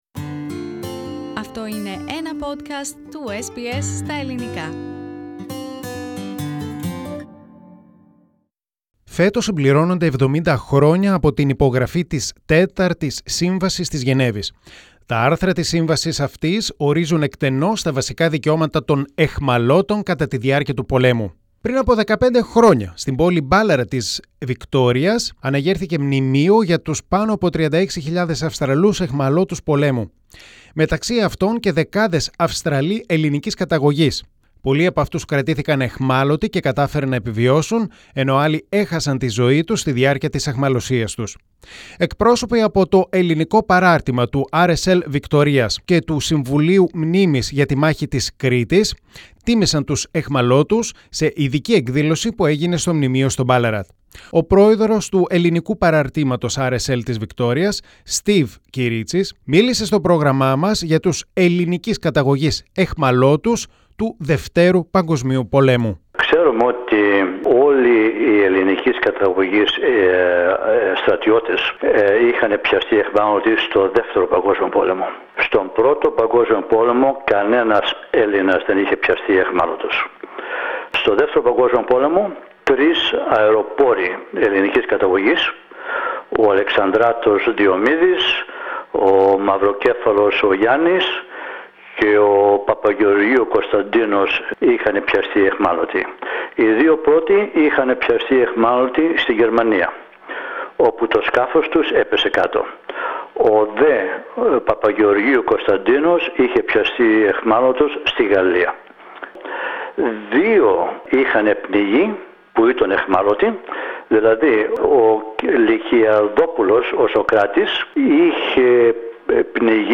Πρόσφατα πραγματοποιήθηκε ειδική τελετή στο Μνημείο Αιχμαλώτων Πολέμου στην επαρχιακή πόλη Ballarat της Βικτώριας για τους Αυστραλούς αιχμαλώτους πολέμου. Το Ελληνικό Πρόγραμμα βρέθηκε εκεί.
From the Ex-Prisoners of War event in Victoria's town of Ballarat.